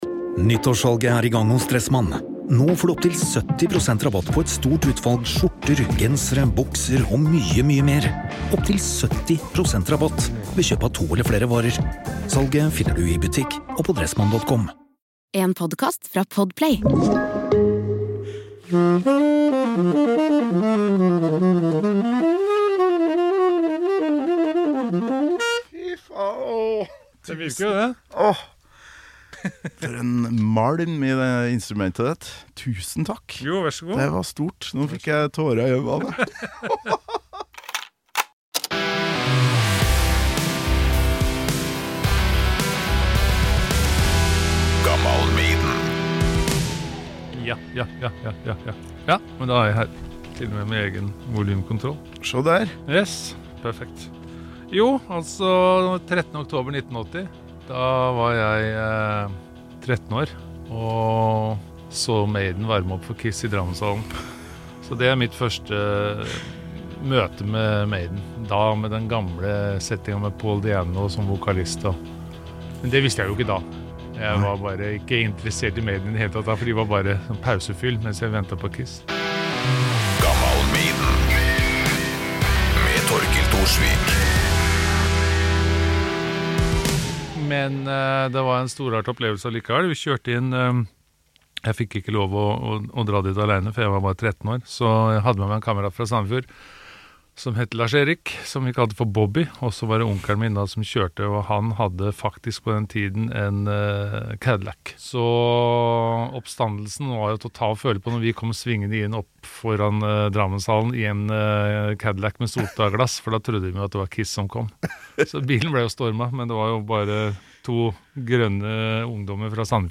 Mitt ungdomsidol og en av landets råeste saksofonister, Petter Wettre er på besøk for å snakke om mine spandex-kledde metalguder Iron Maiden.
Det er bare å sette over svartkjelen og nyte nesten halvannen time med banrdomsminner, inspirasjon, jazz, Paris, Drammenshallen, KISS, Saxon, Priest, Knut Riisnæs, Lester Young og ikke minst noen sekunder med saksofon-spill på en gammal Selmer Mark VI.